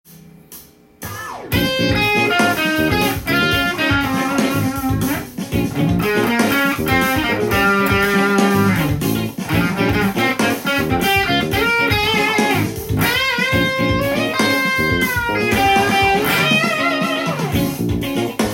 B♭ｍ７ワンコード上　よく使われるスケールを使用して弾いてみました
フュージョンっぽい感じのソロを弾いてみました。
スケールは、B♭ドリアンスケールを使用しています。
フュージョンのような知的な雰囲気のギターソロを弾くことが出来ます。
dorian.solo_.m4a